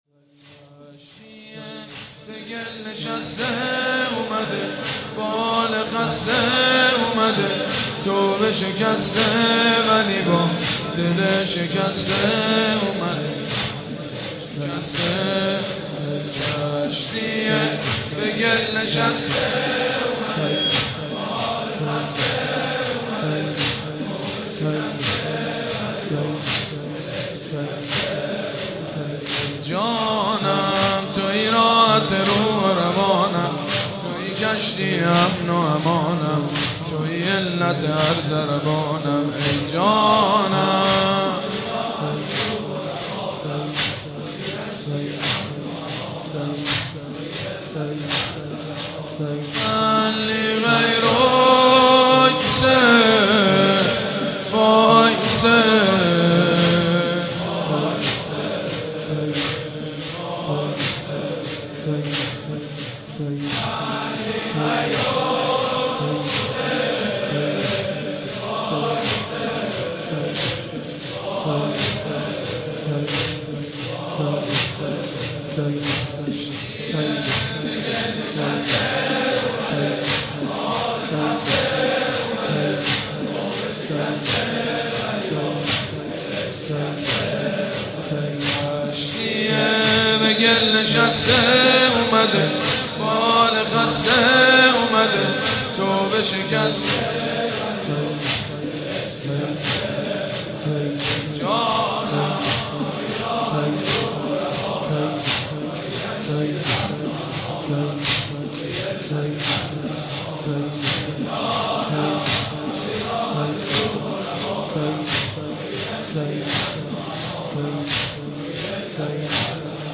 مجموعه مراسم بنی فاطمه در شب تاسوعای 93